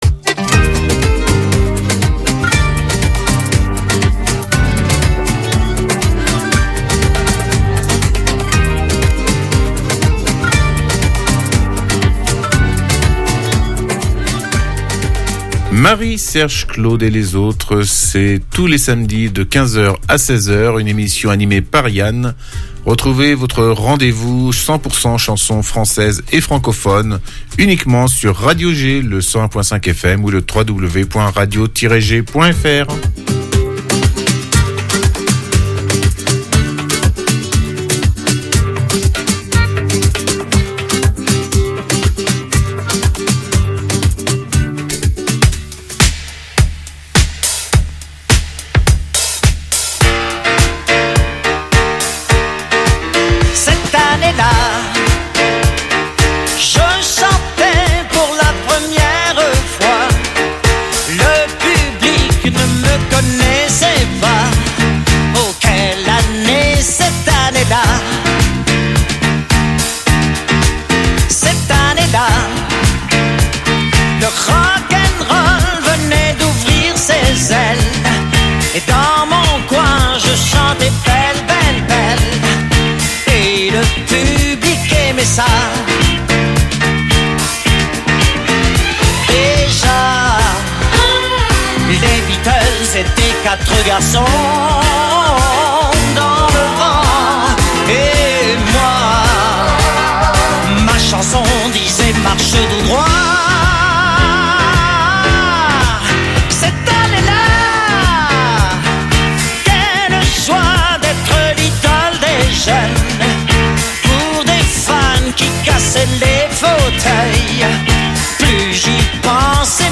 chansons françaises